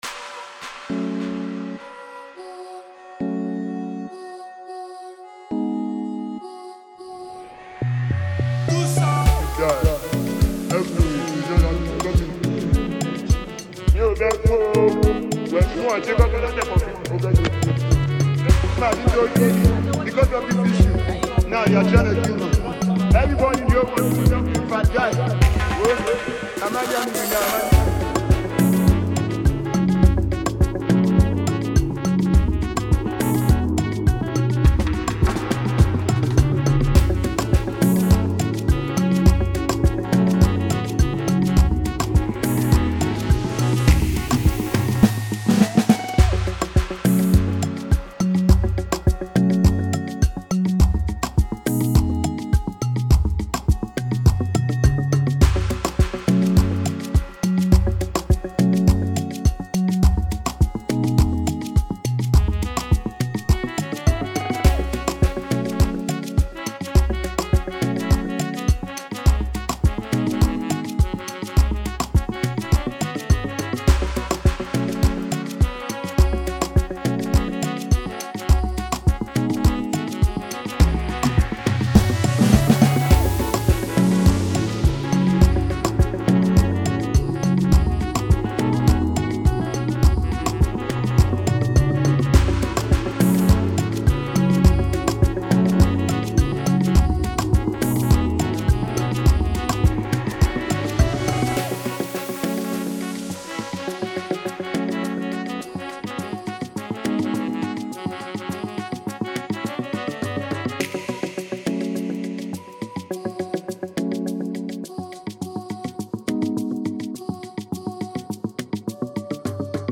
free afrobeat instrumental